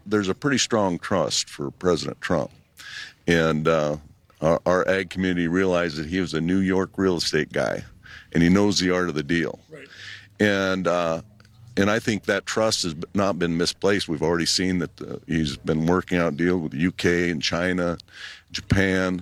Governor Rhoden on Fox & Friends from Sturgis
STURGIS, S.D.(KELO)- Governor Larry Rhoden is wrapping up his time at the Sturgis Motorcycle Rally…but not before making an appearance on Fox & Friends Tuesday morning.